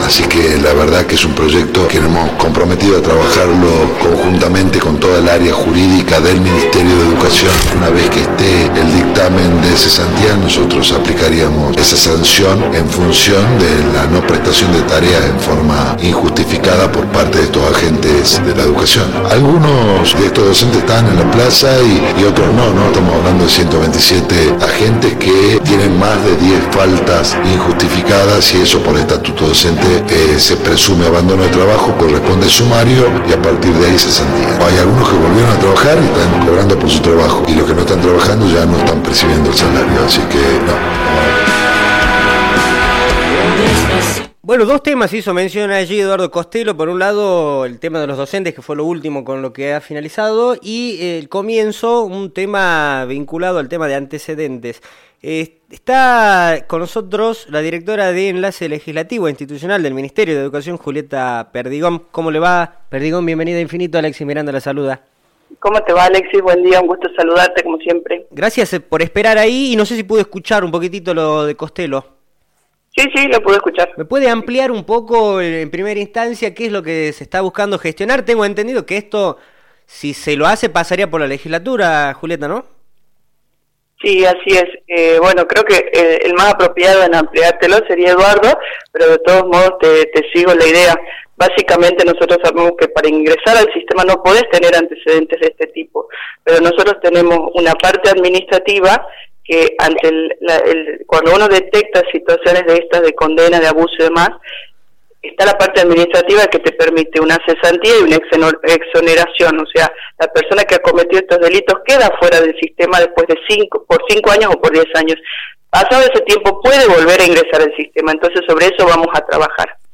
«Para ingresar al sistema no podes tener antecedentes de este tipo«, expresó la funcionaria provincial en diálogo con Radio Infinito.